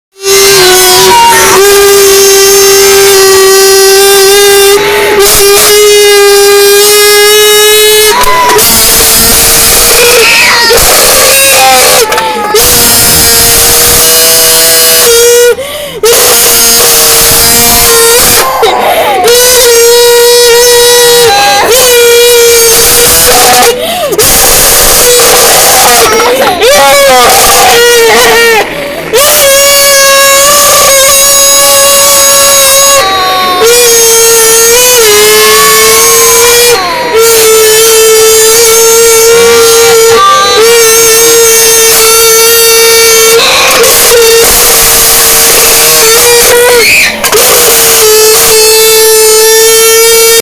Random car sounds with a sound effects free download